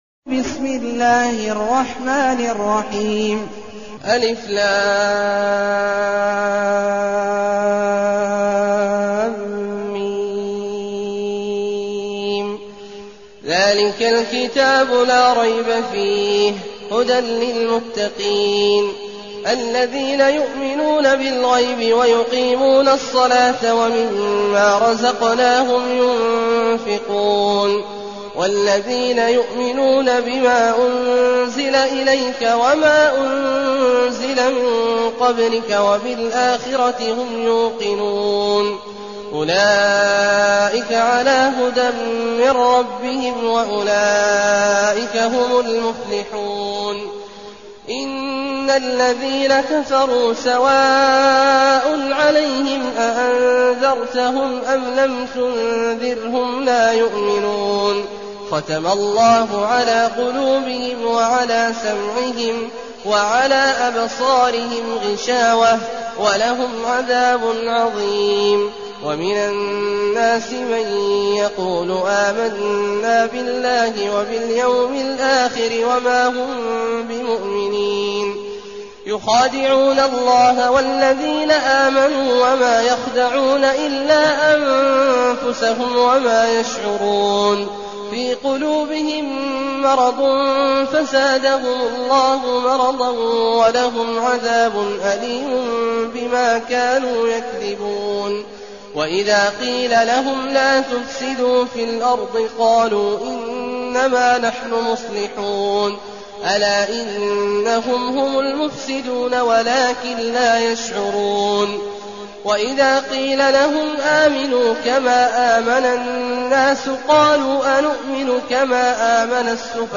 المكان: المسجد النبوي الشيخ: فضيلة الشيخ عبدالله الجهني فضيلة الشيخ عبدالله الجهني البقرة